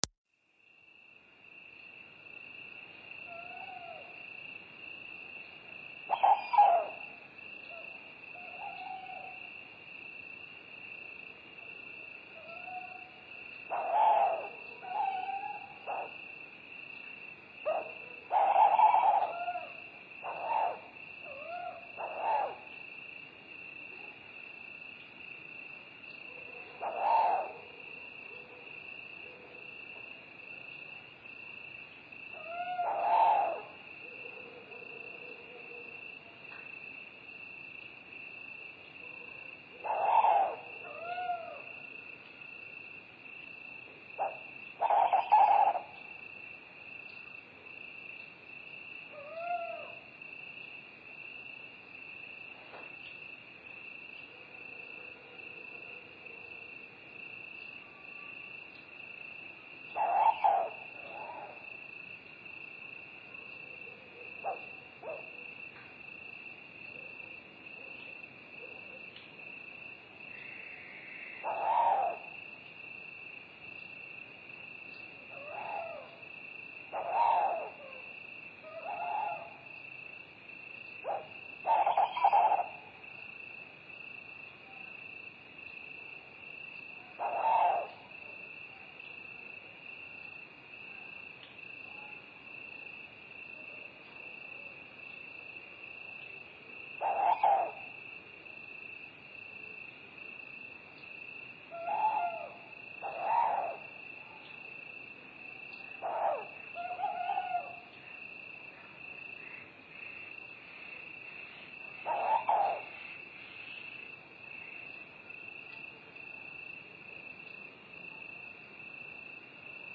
efectos-de-sonido-terror-sonido-del-bosque-por-la-noche.mp3